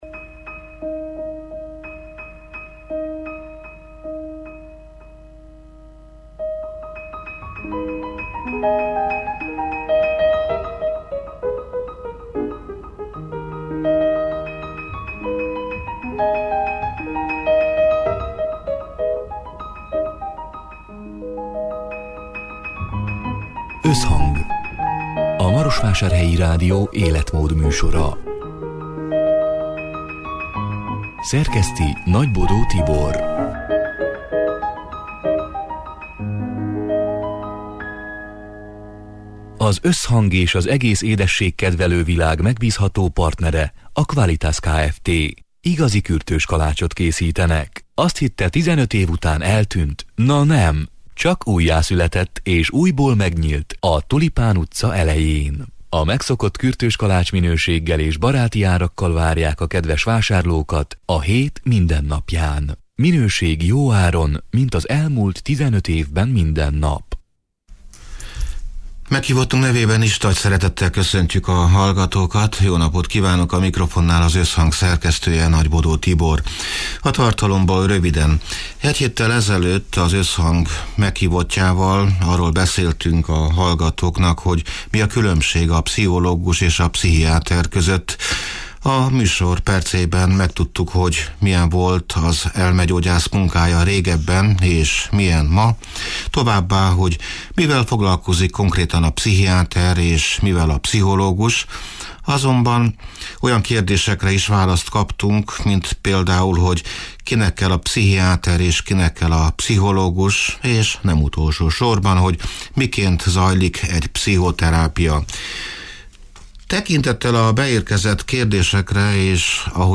(elhangzott: 2023. május 24-én, szerdán délután hat órától élőben)